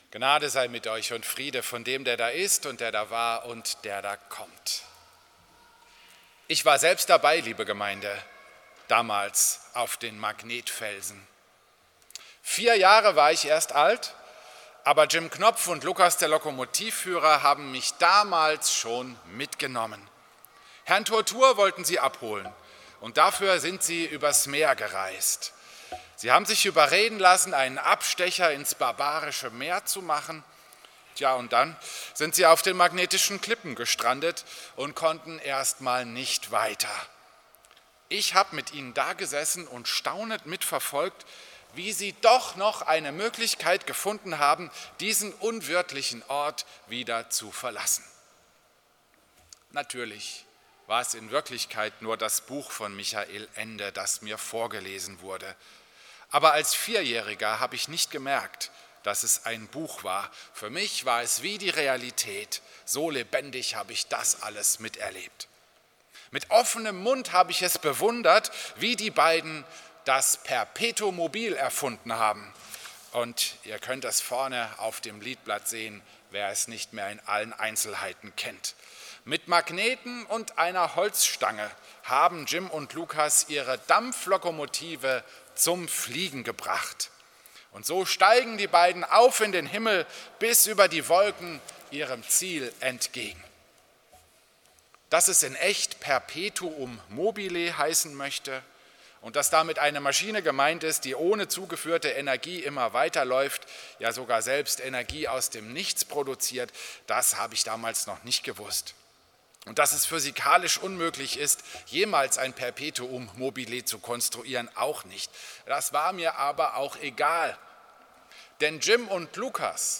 Predigt im Christus-Pavillon Volkenroda am Sonntag Exaudi